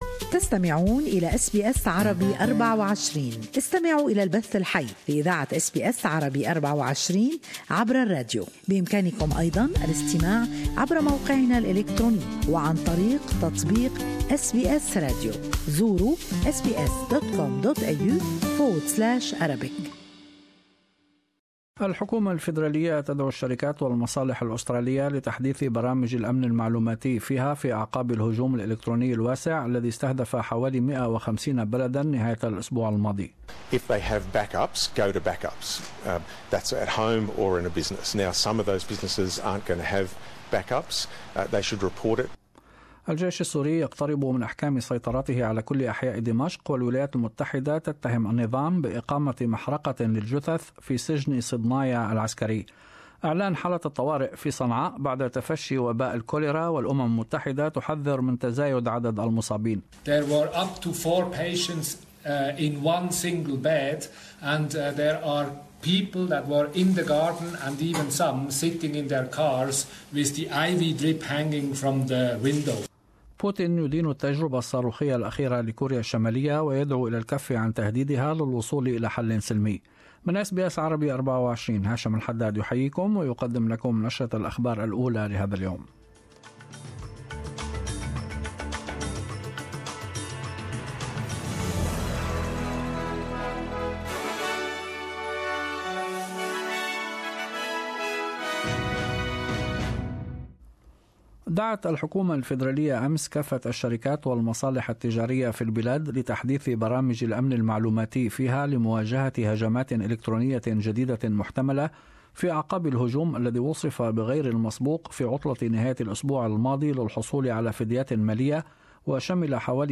Morning news bulletin with latest Australian and world news.